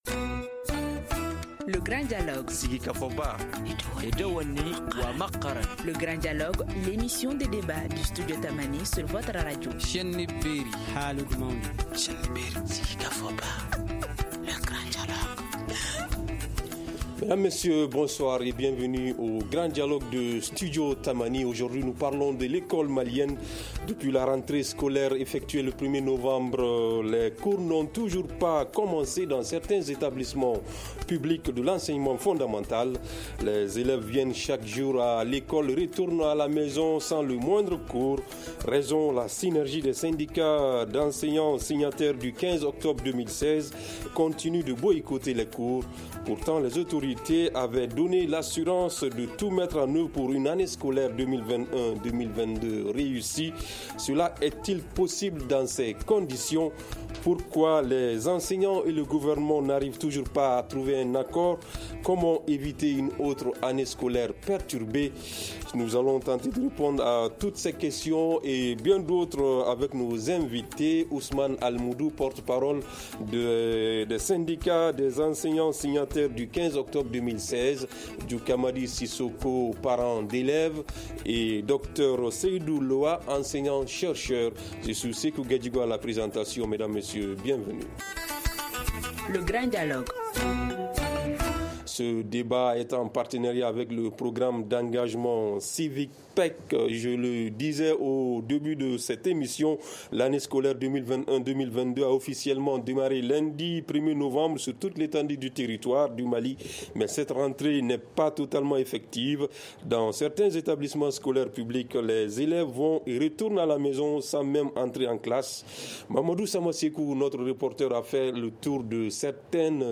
Ce magazine est en partenariat avec le Programme d’engagement civique (PEC)